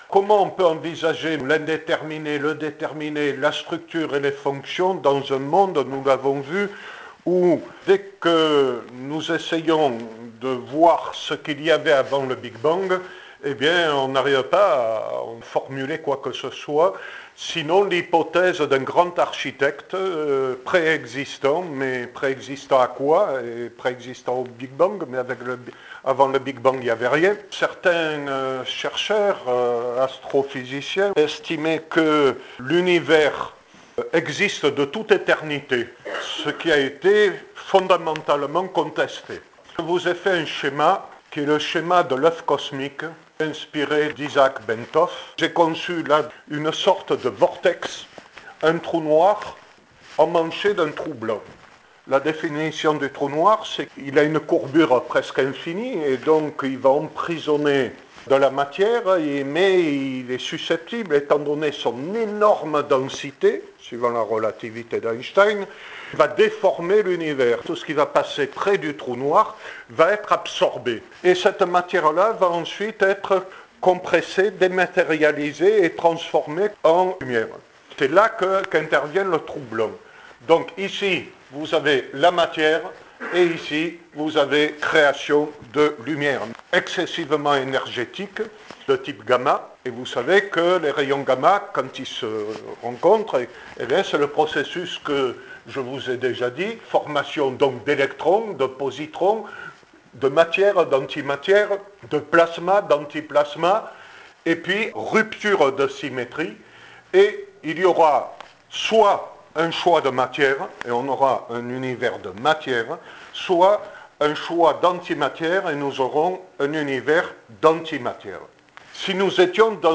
Conférence enregistrée à l'Université du temps libre d'Avignon (janvier 2014)